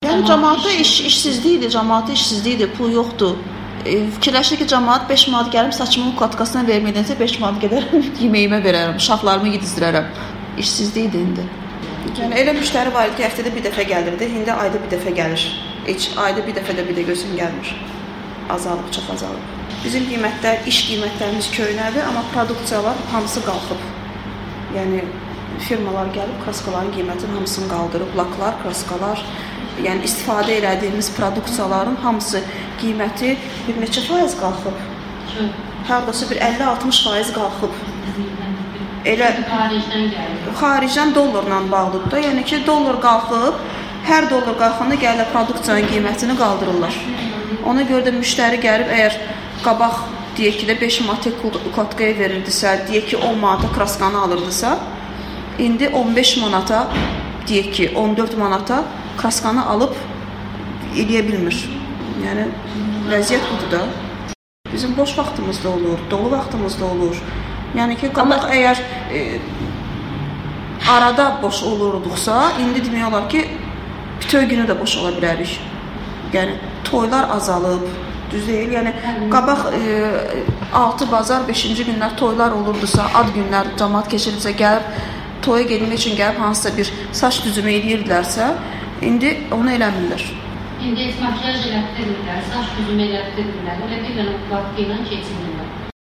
Saç ustası